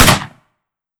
12ga Pump Shotgun - Gunshot B 001.wav